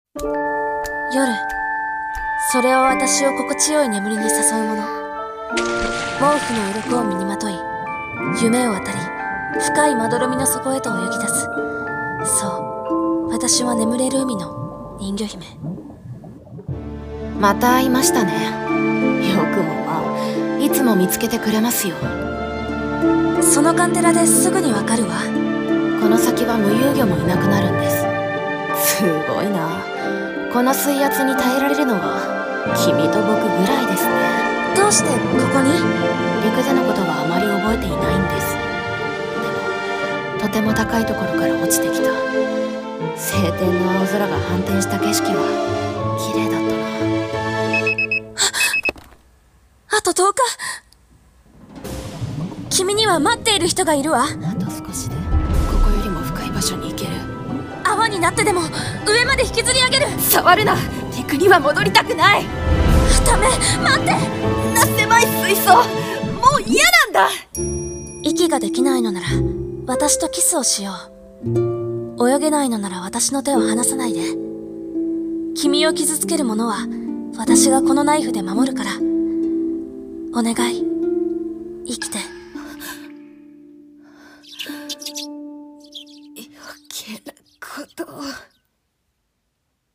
CM風声劇「睡深8400mより」